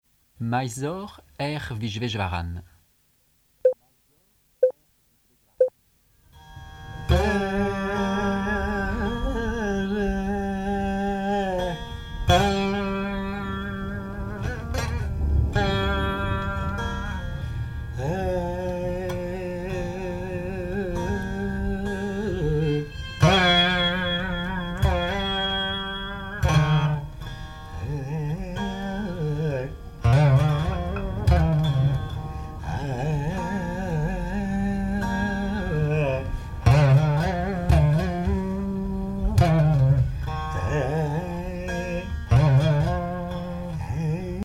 Alap
Musique carnatique
Pièce musicale inédite